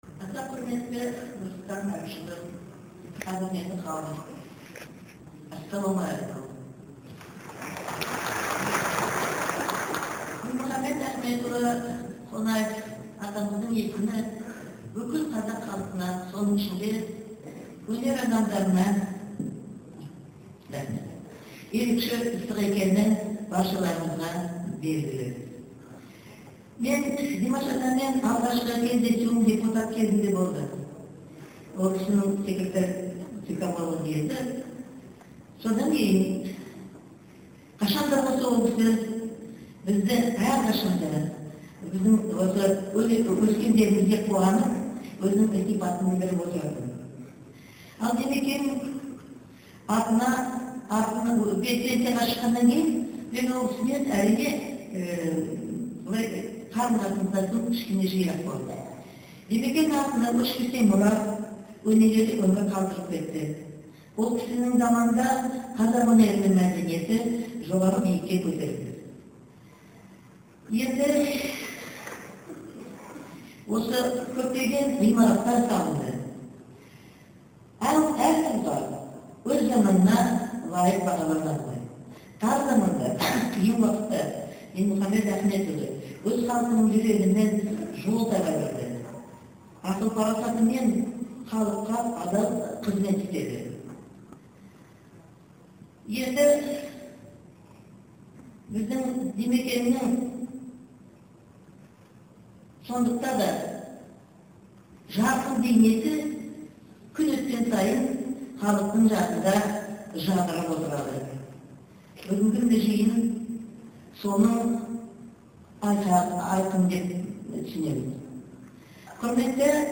Бибігүл Төлегенованың сөзі